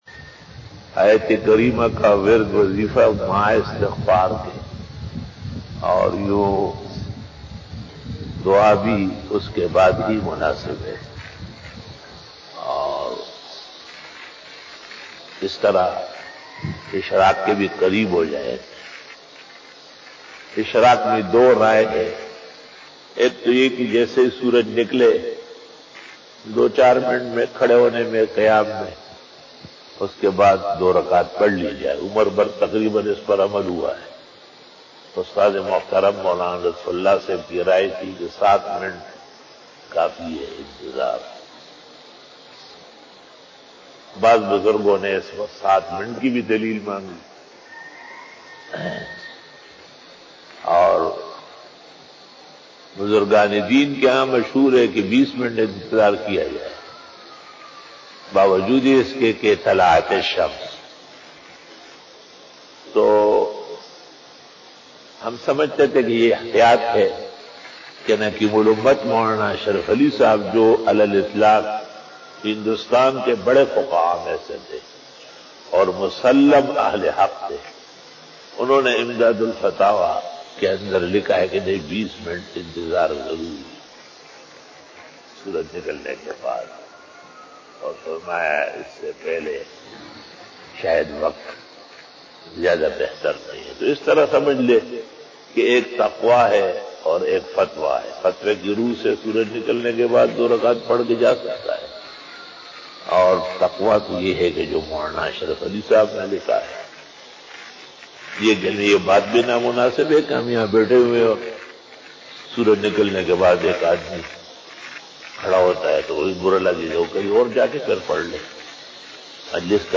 Fajar bayan 09 October 2020 (21 Safar ul Muzaffar 1442HJ) Friday
After Namaz Bayan